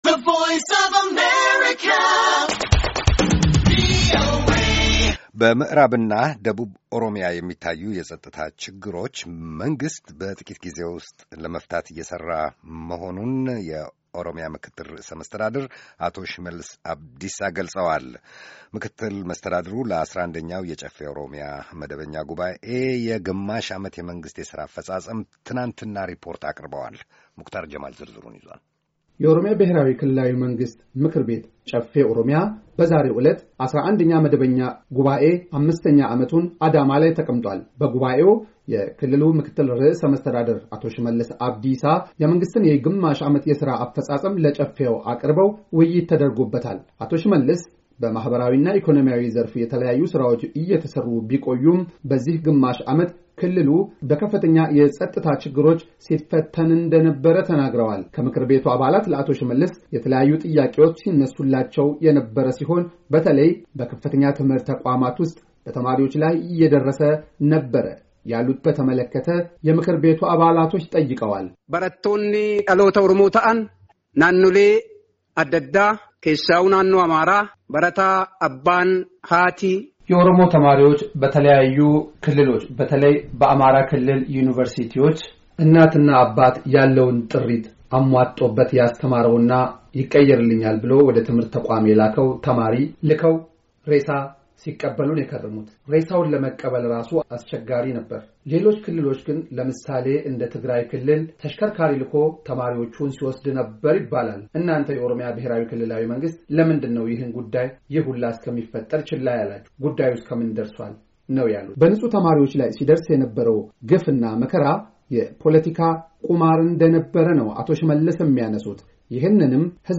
በምዕራብ እና ደቡብ ኦሮሚያ የሚታዩ የፀጥታ ችግር መንግሥት በጥቂት ግዜ ውስጥ ለመፍታት እየሰራ መሆኑን የኦሮሚያ ምክትል ርዕሰ መስተዳደር አቶ ሽመልስ አብዲሳ ገለፁ። አቶ ሽመልስ ለ11ኛ የጨፌ ኦሮሚያ መደበኛ ጉባኤ ዛሬ የግማሽ ዓመት የመንግሥት ሥራ አፈጻጸም አቅርበዋል።